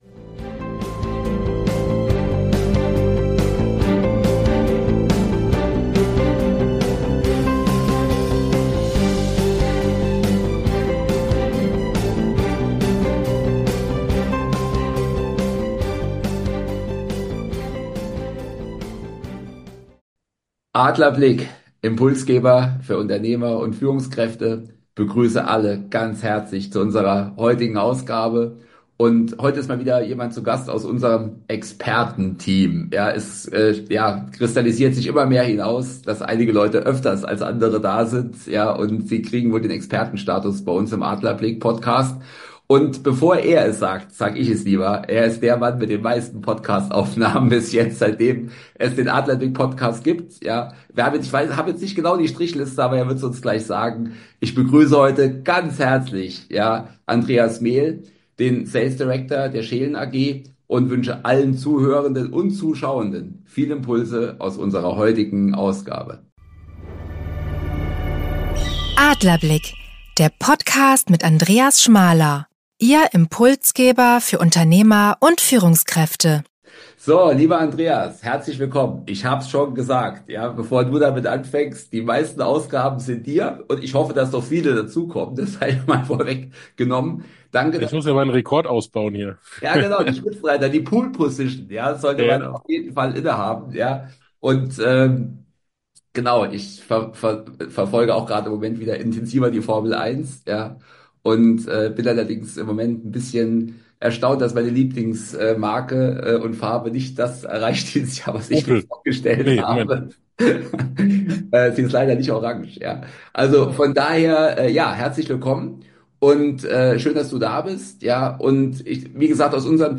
Ein Gespräch über Haltung, Verantwortung und den Mut zur Veränderung – mit vielen Impulsen für alle, die Führung nicht nur übernehmen, sondern auch gestalten wollen.